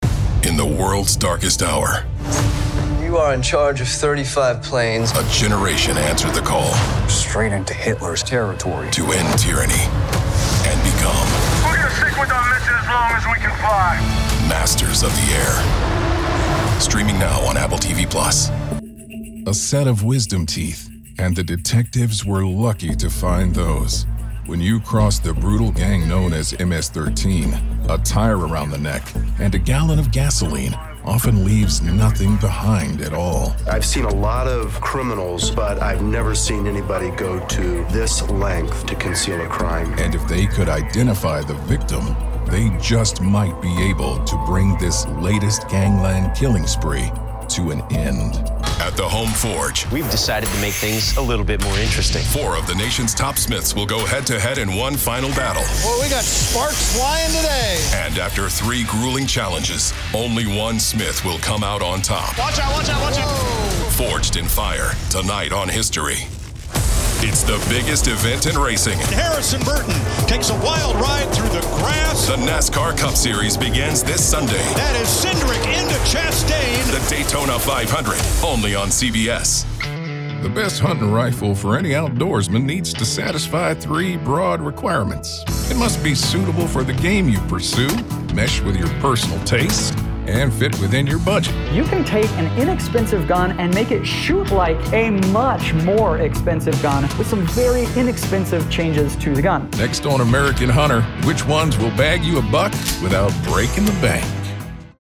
Promo + Narration demo
Southern, Western, NYC/Brooklyn, Mid-Atlantic
Middle Aged
Senior